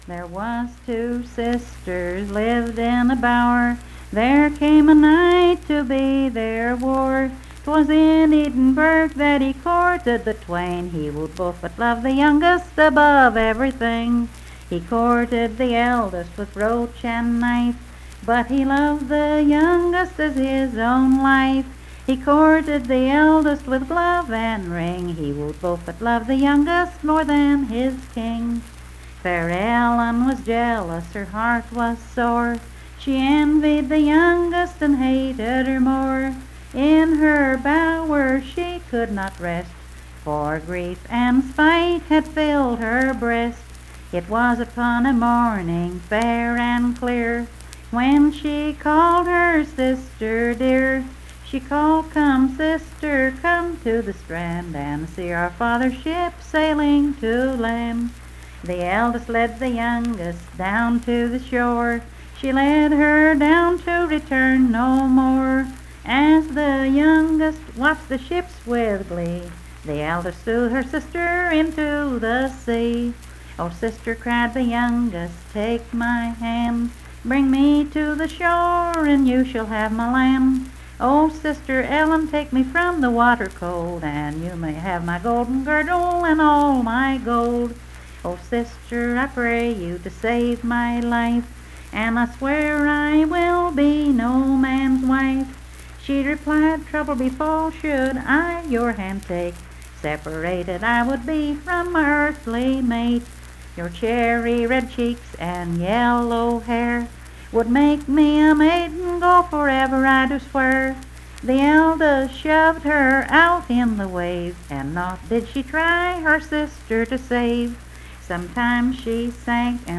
Unaccompanied vocal music
Verse-refrain 15(4).
Performed in Coalfax, Marion County, WV.
Voice (sung)